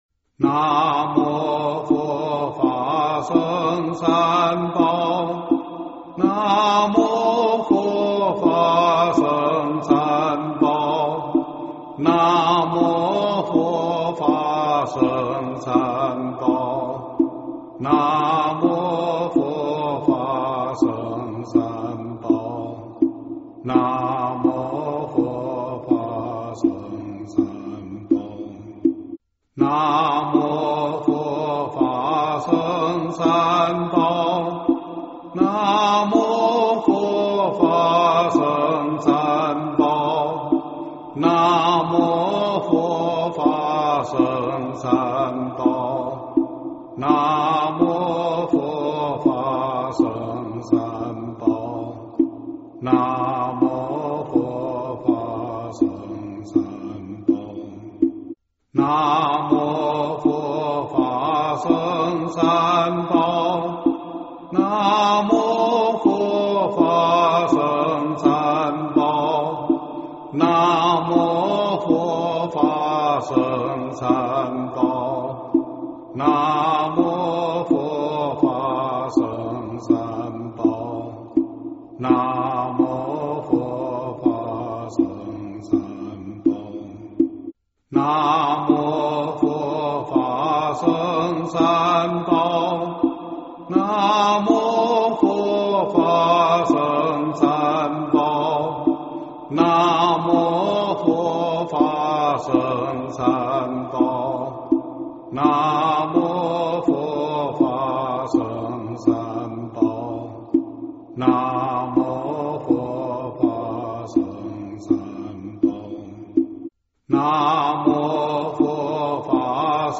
经忏
佛音
佛教音乐